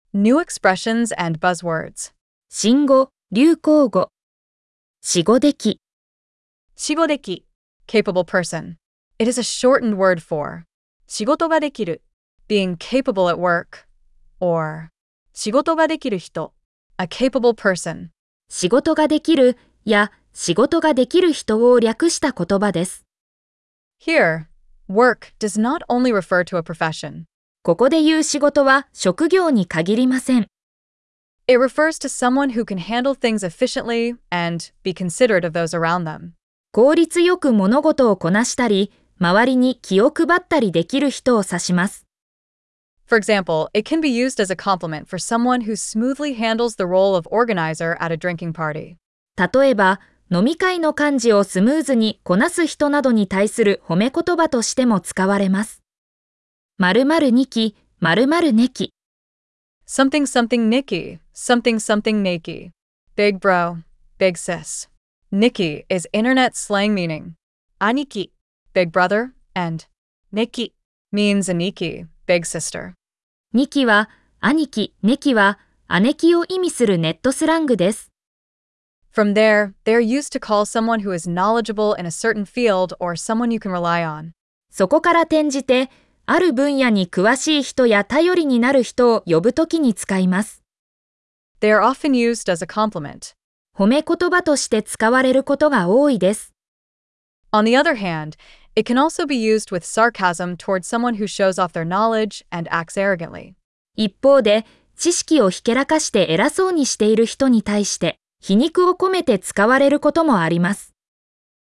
🗣 pronounced: xx niki / xx neki